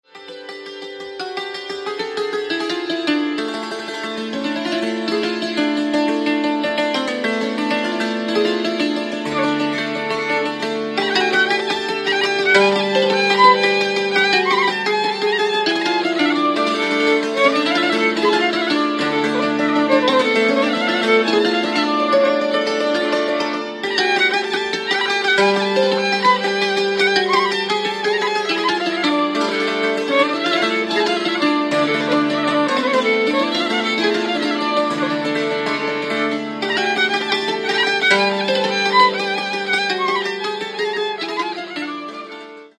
Каталог -> Народная -> Аутентичное исполнение